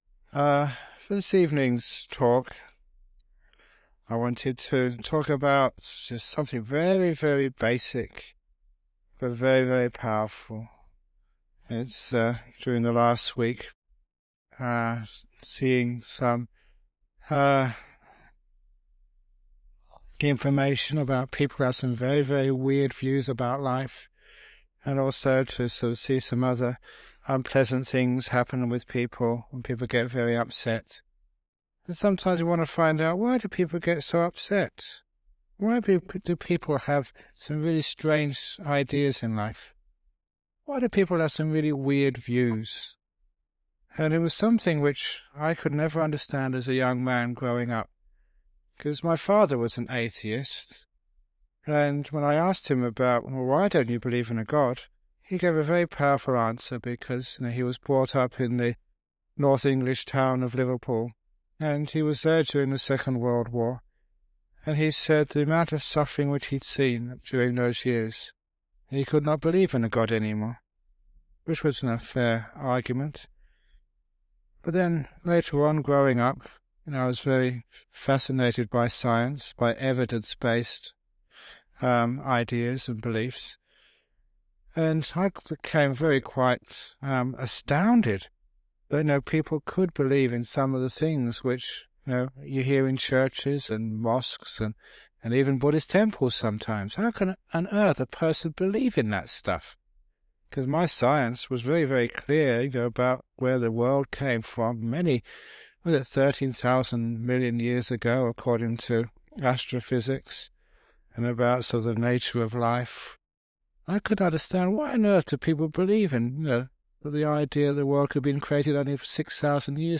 Ajahn Brahm gives a talk about the origin of human views - including questioning why some people can believe in really crazy ideas - by looking at causality inside the mind and how our belief systems are shaped. And also how we can use an understanding of this process to bring our beliefs and views into line with reality.
It has now been remastered and published by the Everyday Dhamma Network, and will be of interest to his many fans.